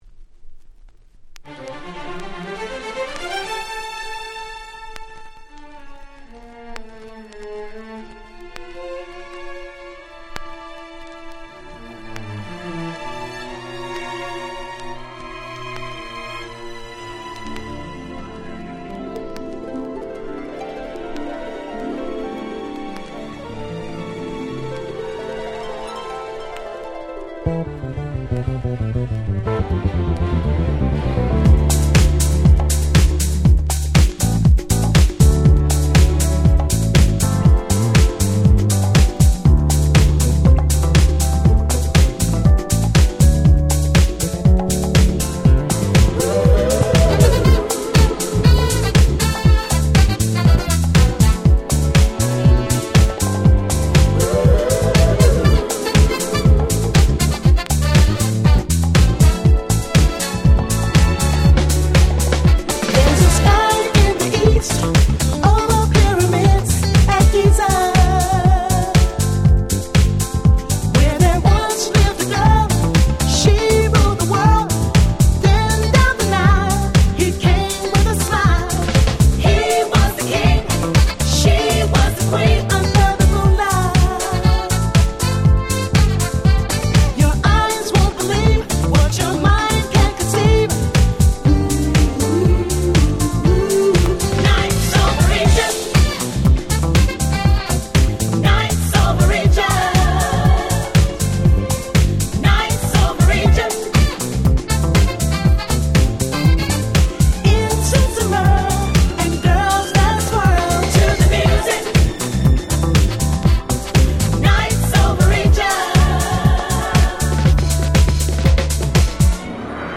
99' Super Nice Cover Vocal House !!
ボーカルハウス